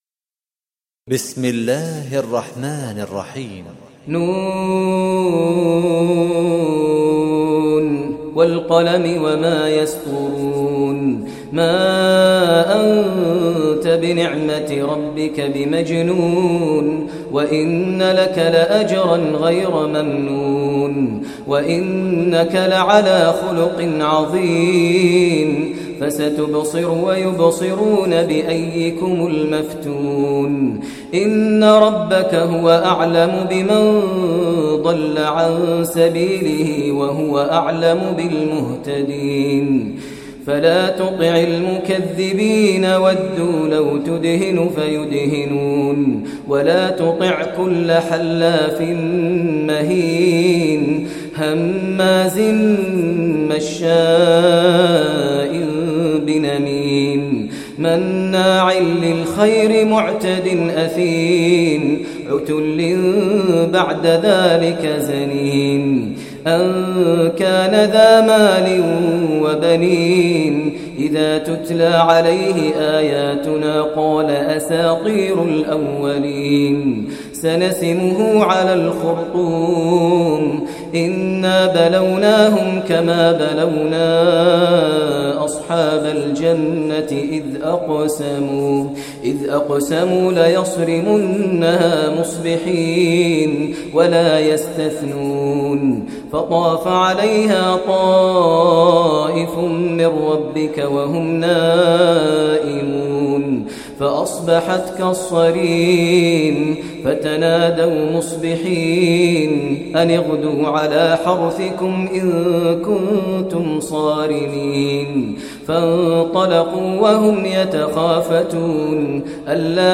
Surah Qalam Recitation by Sehikh Maher Mueaqly
Surah Qalam, listen online mp3 tilawat / recitation in Arabic in the voice of Imam e Kaaba Sheikh Maher al Mueaqly.